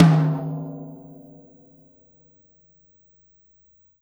Tom Shard 07.wav